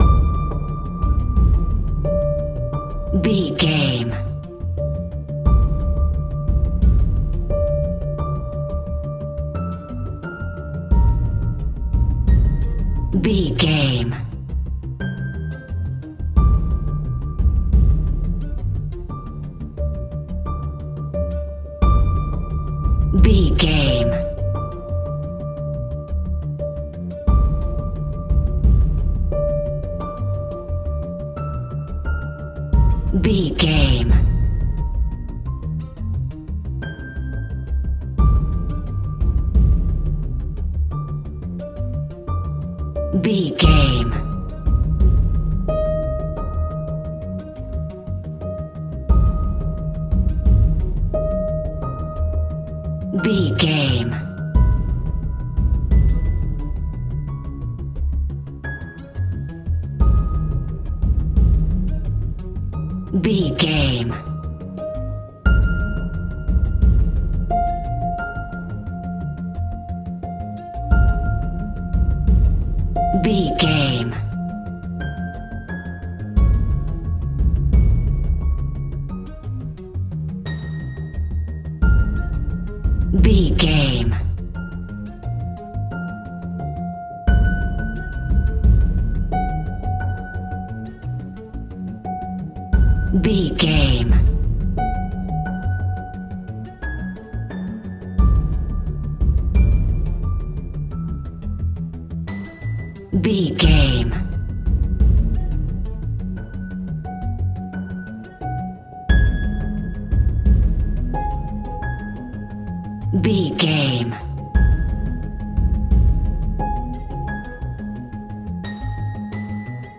royalty free music
Thriller
Aeolian/Minor
D
scary
ominous
dark
suspense
haunting
eerie
strings
percussion
instrumentals